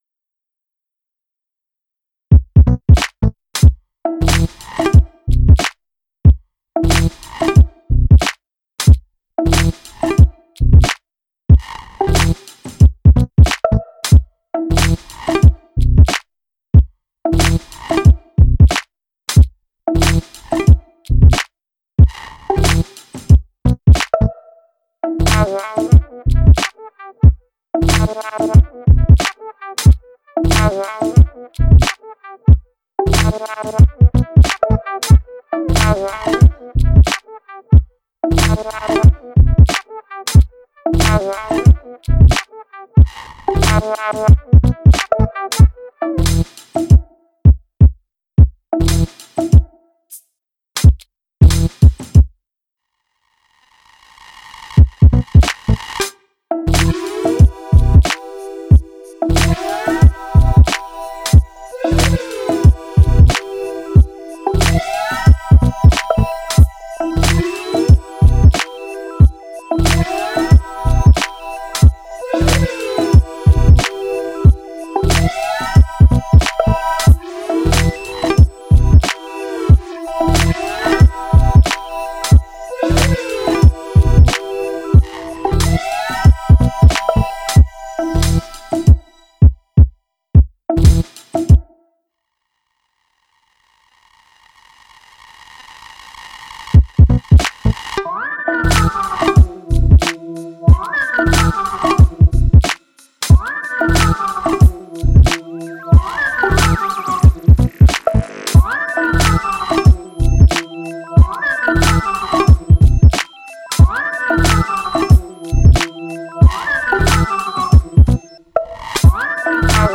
Cool groovy bleepy slow beat with hypnotic synth line.